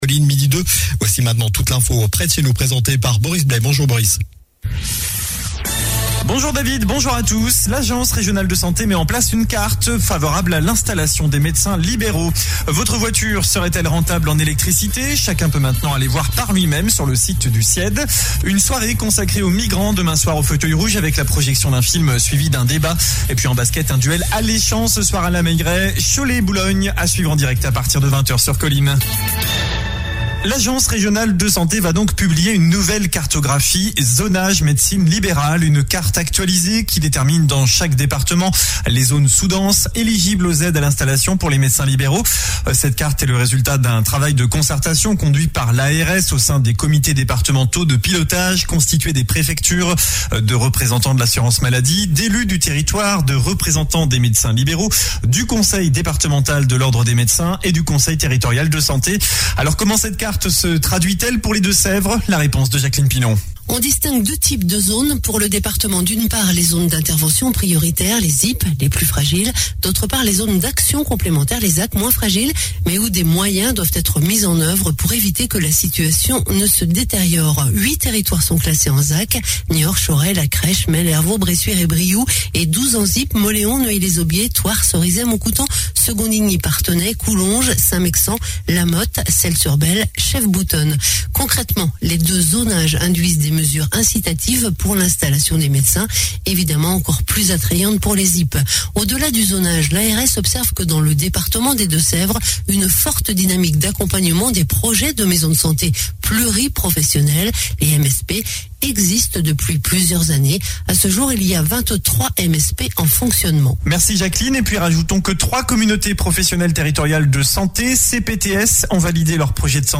Journal du mardi 03 mai (midi)